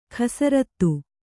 ♪ khasarattu